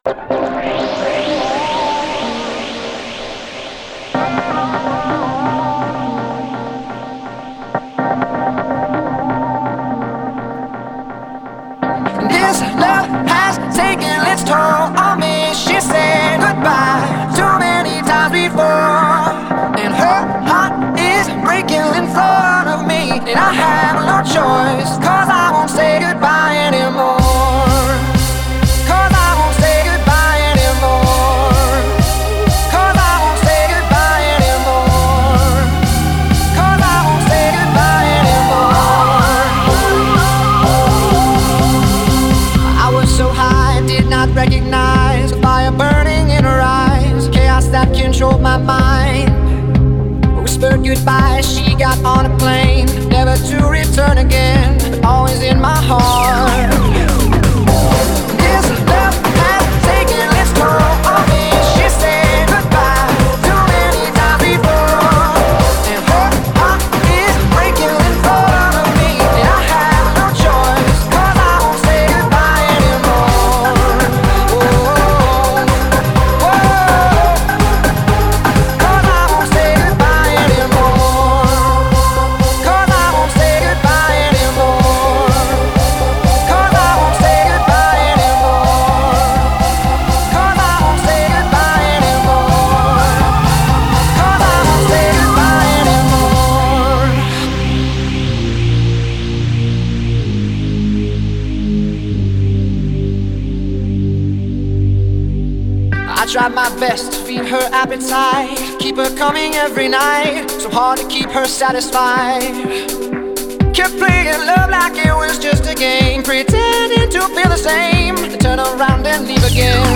DJ舞曲，都是快节奏、劲爆的音乐。